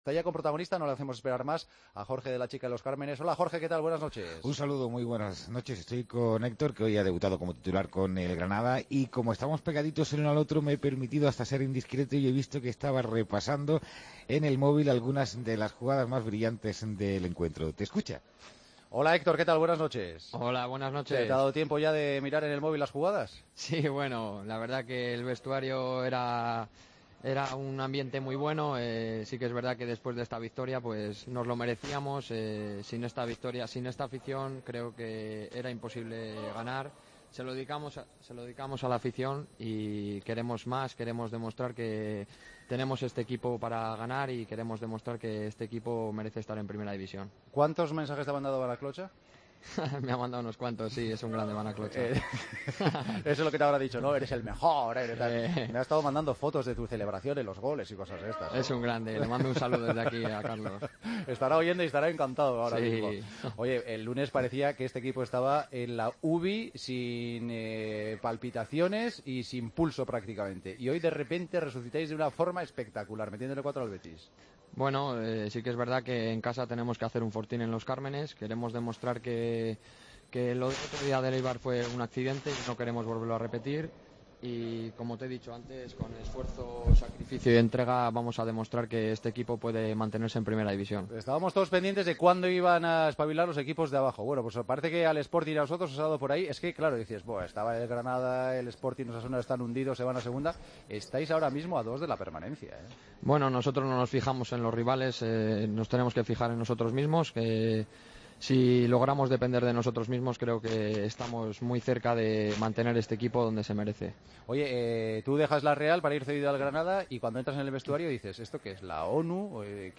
Hablamos con el jugador del Granada tras la victoria frente al Betis: "En el vestuario teníamos un ambiente muy bueno.